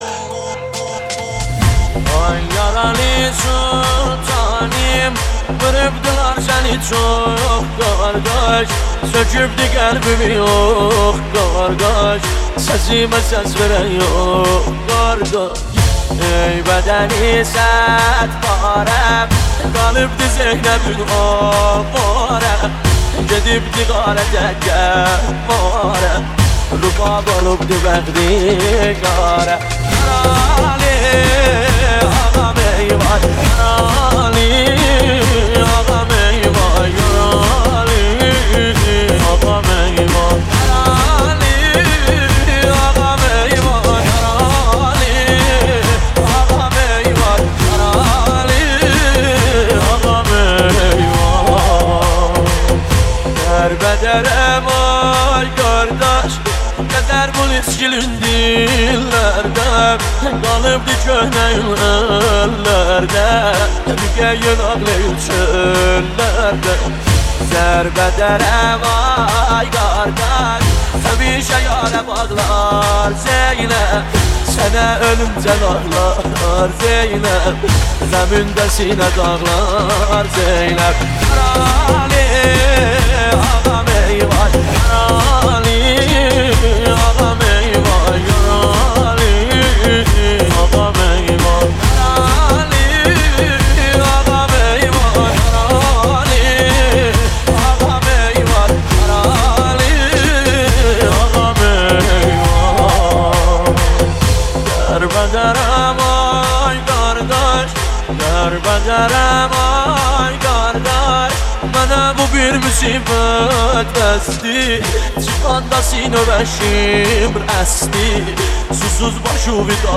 نوحه جدید ترکی